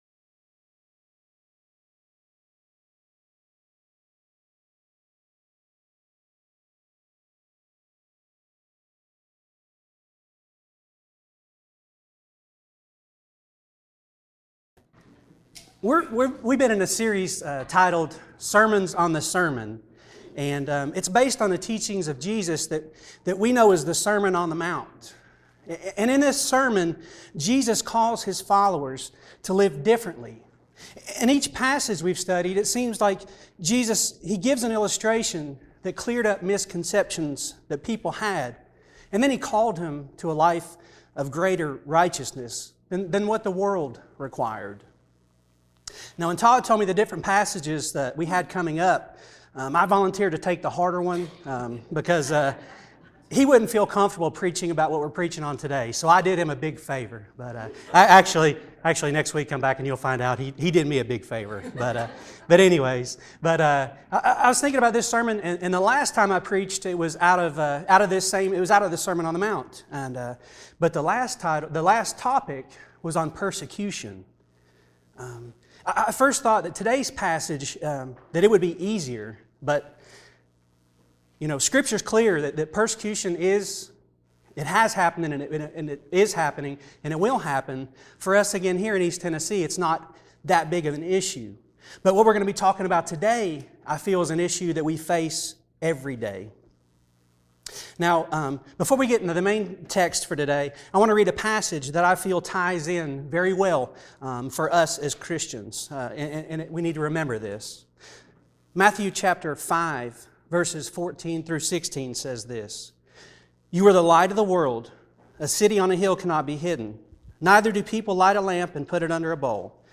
Sermons on THE Sermon Integrity Matters Speaker